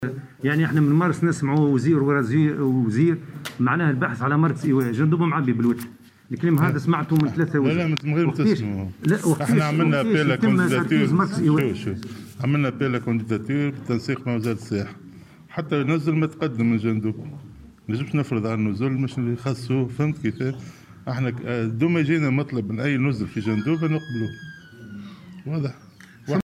أكد وزير الصحة فوزي مهدي اليوم السبت على هامش زيارة الى جندوبة أنه لم يتقدّم أي نزل بجهة جندوبة لتحويل النزل إلى مركز لإيواء المصابين بكورونا.